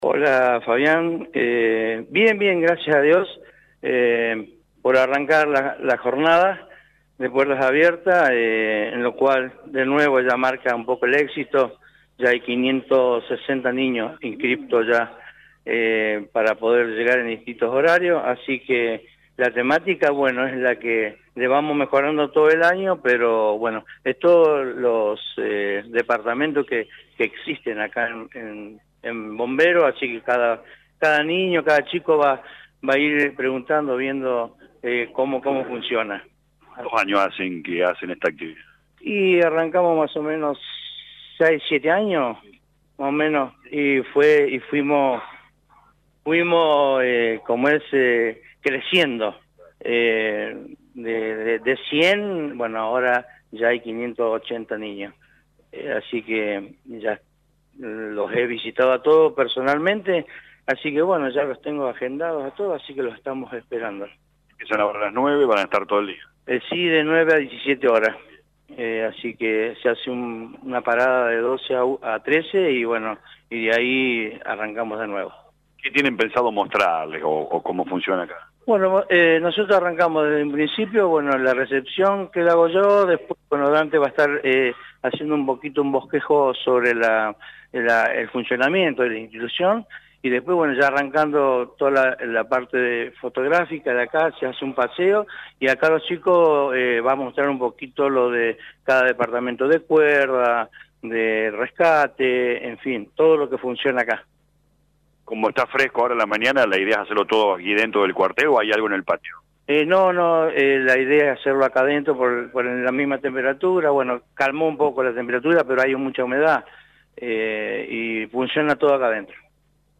LA RADIO 102.9 FM visitó el cuartel y dialogó con los encargados de la jornada: